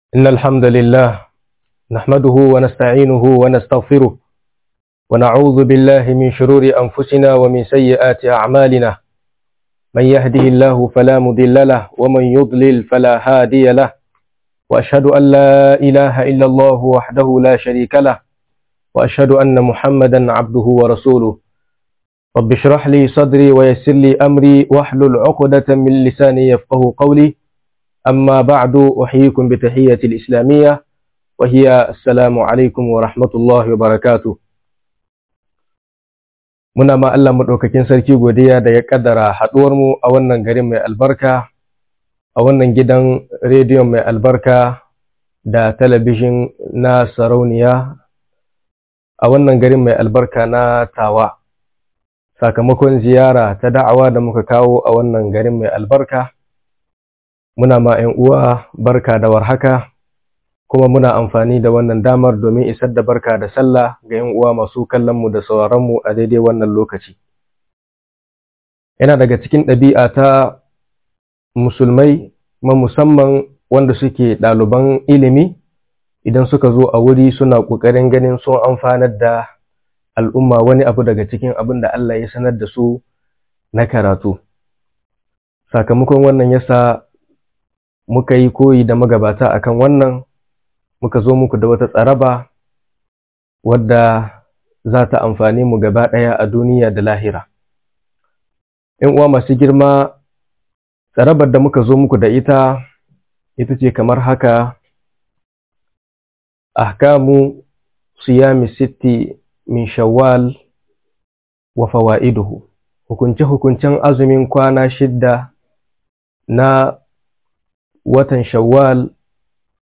Hukunce-hukuncen Sitta shawal - MUHADARA